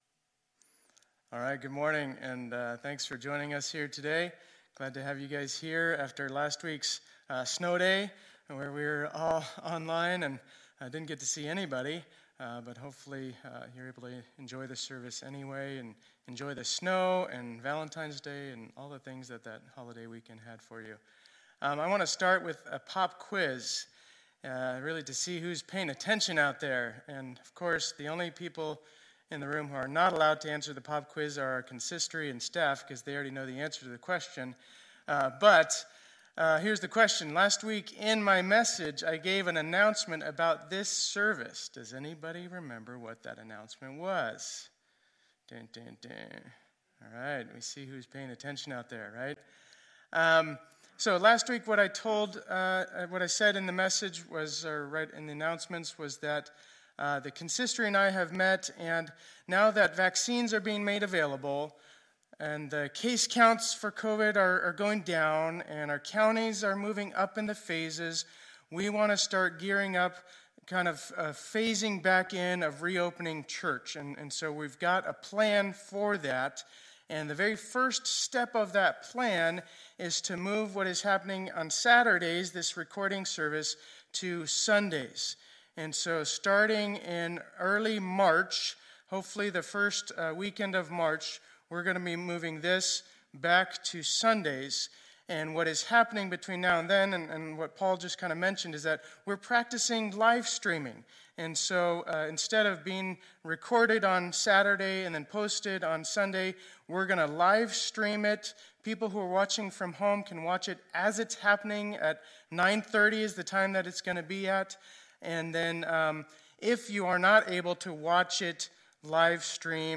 2021-02-21 Sunday Service